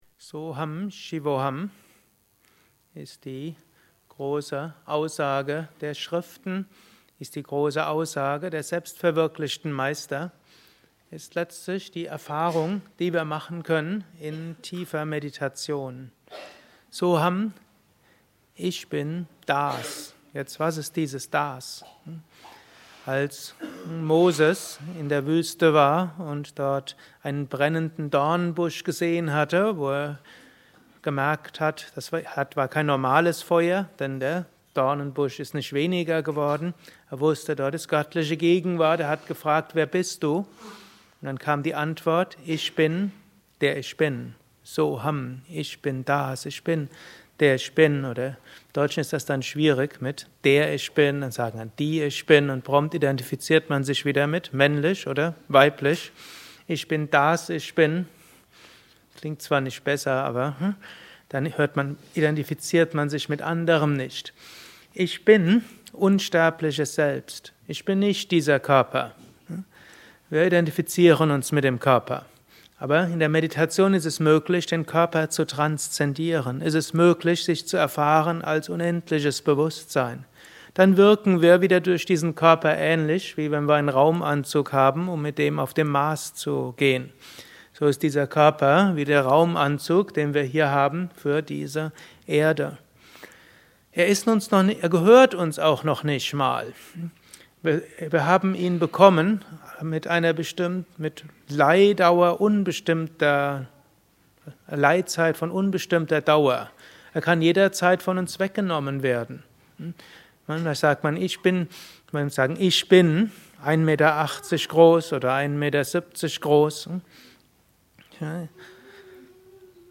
Gelesen im Anschluss nach einer Meditation im Haus Yoga Vidya Bad Meinberg.